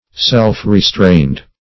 Self-restrained \Self`-re*strained"\, a.